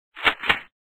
ammo_mono_01.ogg